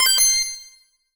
Universal UI SFX / Basic Menu Navigation
Menu_Navigation02_Options.wav